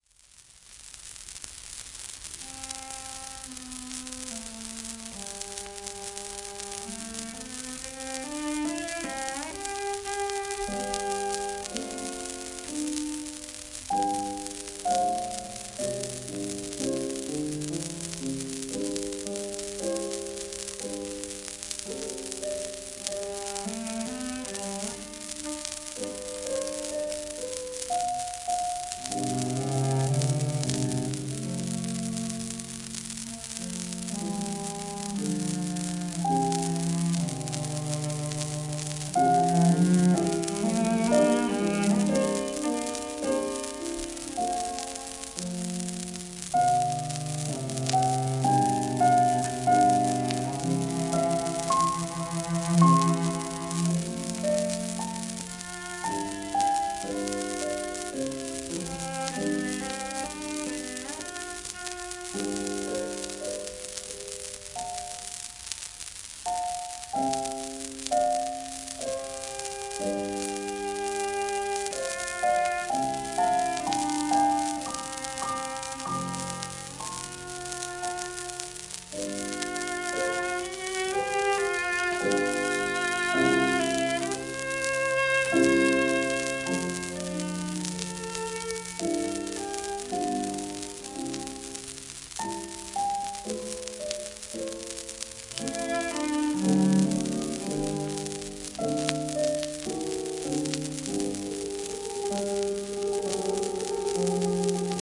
1936年ロンドン録音